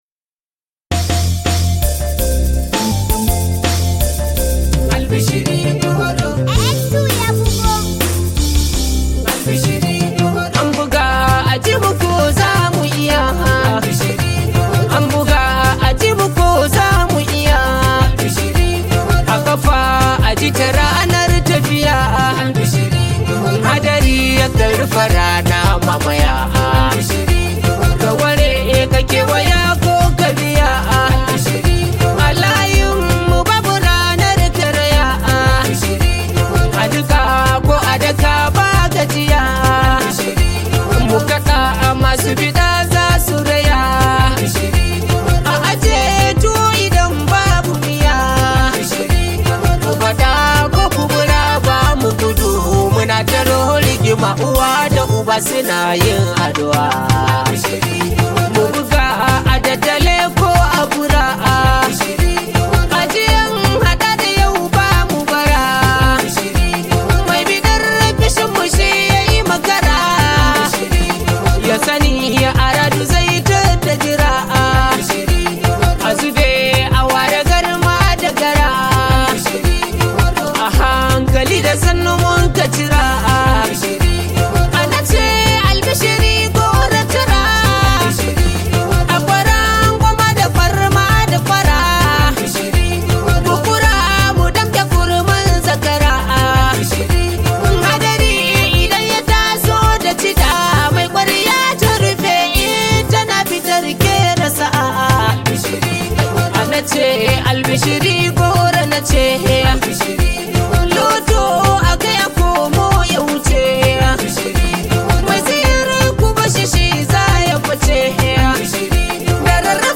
a romantic song for first wives.